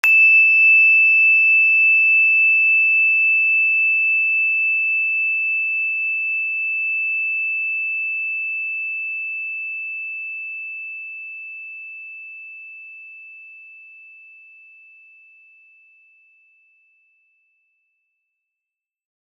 energychime_plastic-E6-pp.wav